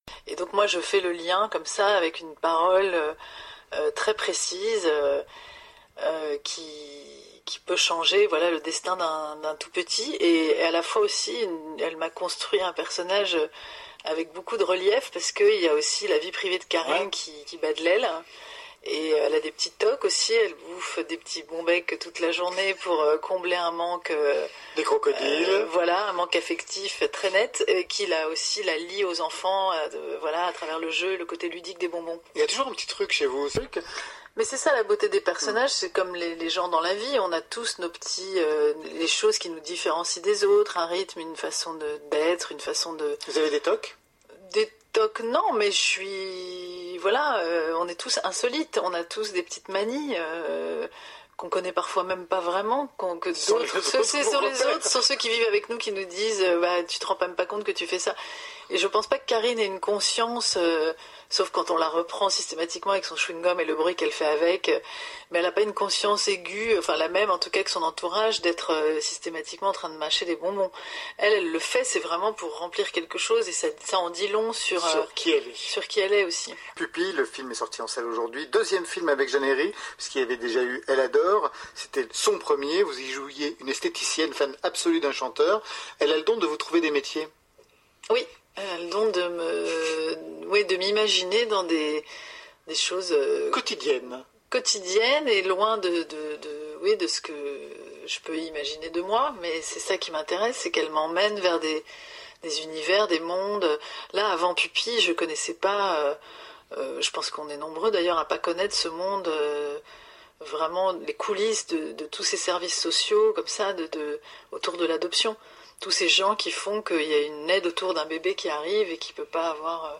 Et voici ce qu’en racontait Sandrine Kiberlain il y a quelques jours à la radio, avec son naturel et sa grande sincérité. Touchante elle aussi, comme toujours.